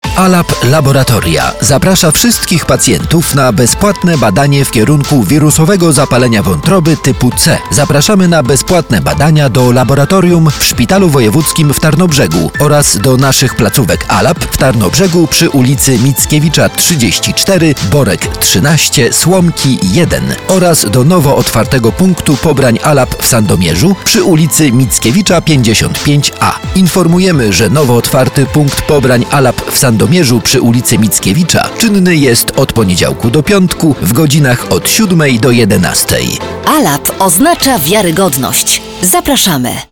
Male 30-50 lat
A voice with a low, sandy timbre, constantly seeking new challenges.
Reklama radiowa